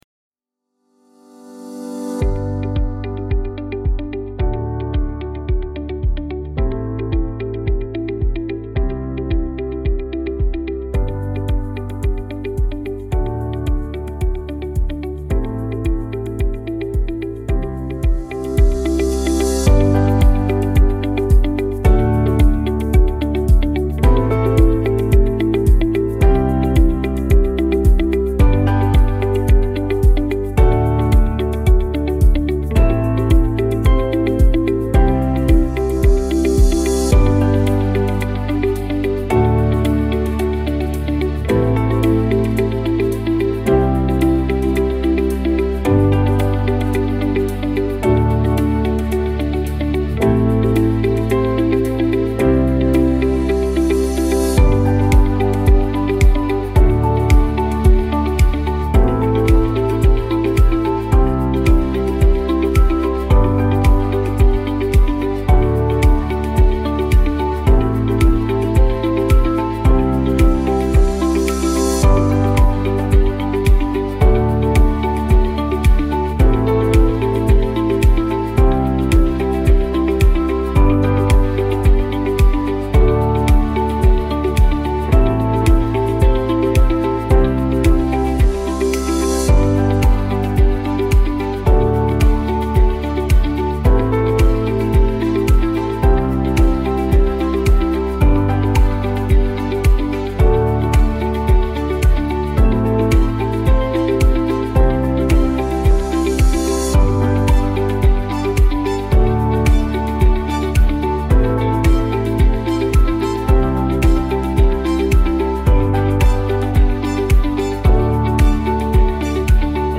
uplifting corporate theme with optimism.